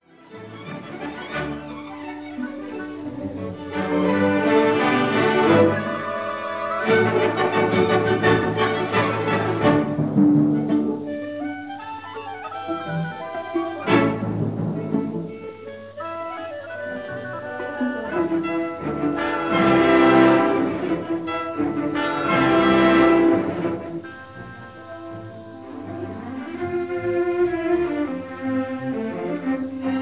Thames Street Studio London.